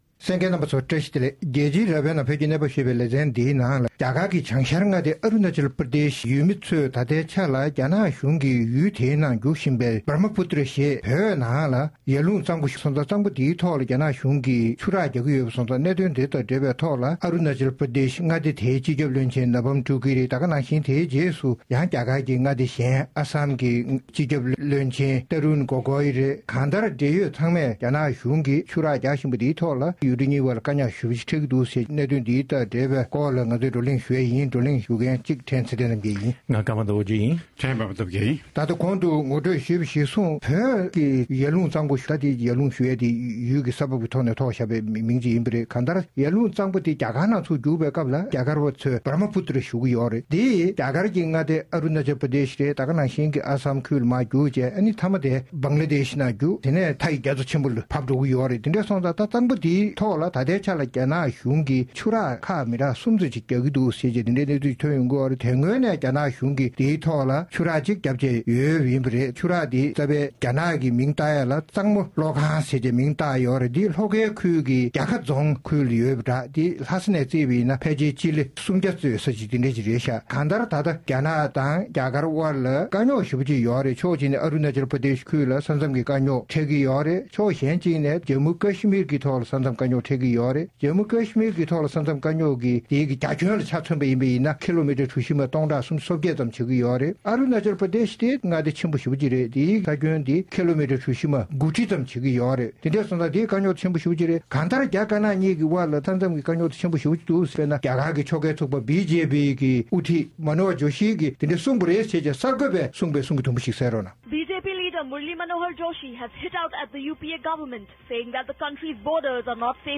༄༅། །རྒྱལ་སྤྱིའི་རྭ་བའི་ནང་གི་བོད་ཀྱི་གནས་བབ་ཞེས་པའི་ལེ་ཚན་ནང་། རྒྱ་ནག་གཞུང་གིས་བོད་ཀྱི་ཡར་ཀླུངས་གཙང་པོའི་སྟེང་ཆུ་རགས་རྒྱག་བཞིན་པ་དེས་རྒྱ་གར་ལ་དོ་ཕོག་ཡོང་ངེས་ཡིན་པ་རེད། སོང་ཙང་ང་ཚོ་རླུང་འཕྲིན་ཁང་ནས་རྒྱ་དཀར་ནག་གཉིས་ཀྱི་དབར་ཆུ་དང་ས་མཚམས་ཀྱི་དཀའ་ངལ་འཕྲད་བཞིན་པའི་སྐོར་བགྲོ་གླེང་ཞུས་པ་ཞིག་གསན་རོགས་གནང་།།